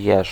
Ääntäminen
Ääntäminen : IPA: [jɛʃ] Tuntematon aksentti: IPA: [jɛʂ] Haettu sana löytyi näillä lähdekielillä: puola Käännös Ääninäyte 1. egel {m} Suku: m .